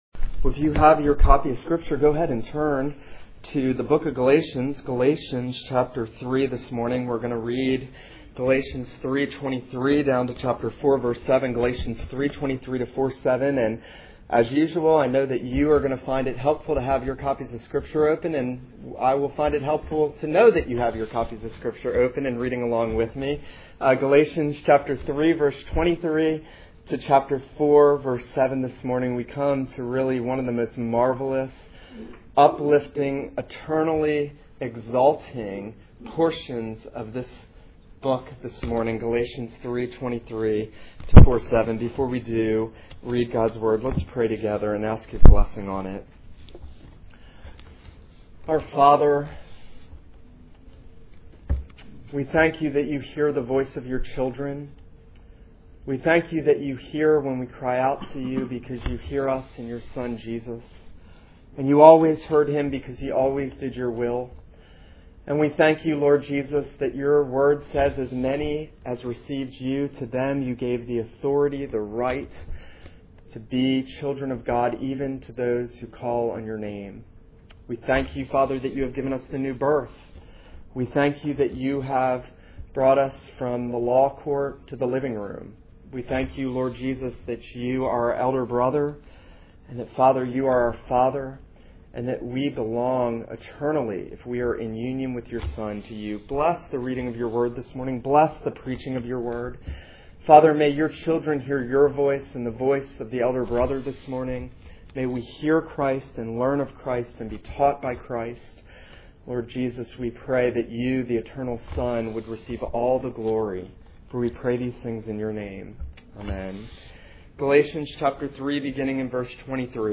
This is a sermon on Galatians 3:23-4:7.